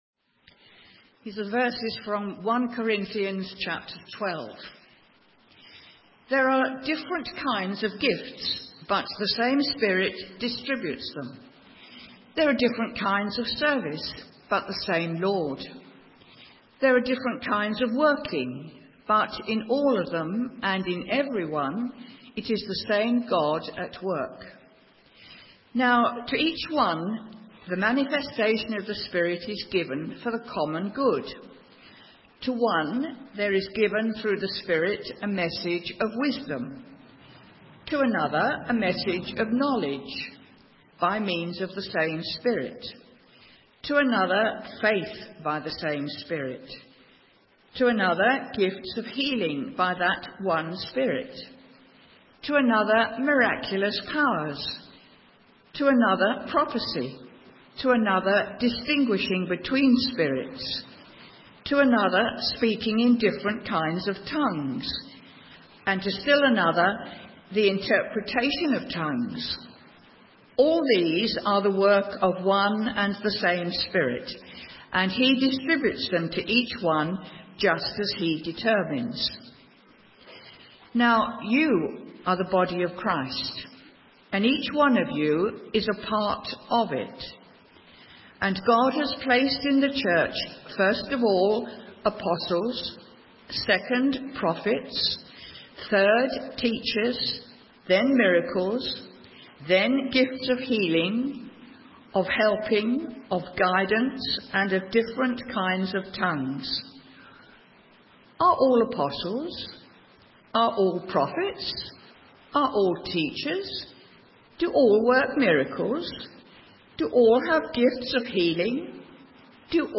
A message from the series "Mindset Shift."